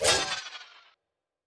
Special & Powerup (29).wav